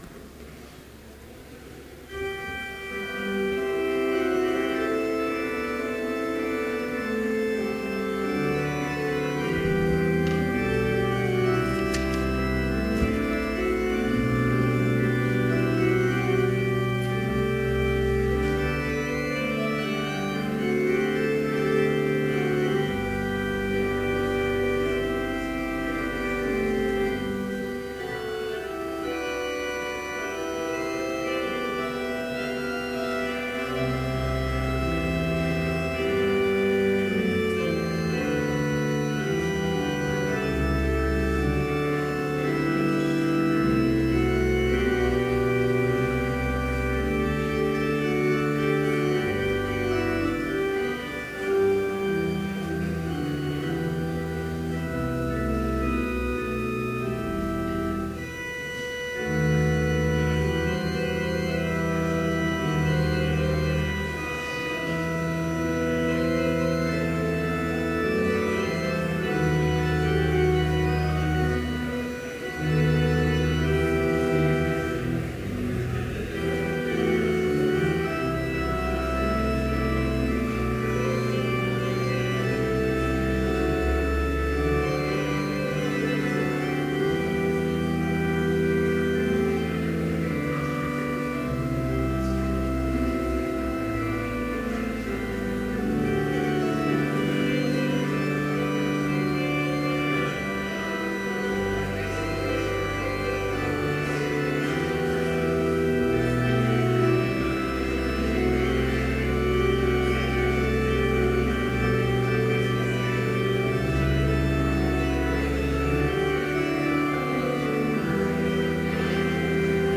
Complete service audio for Chapel - February 2, 2015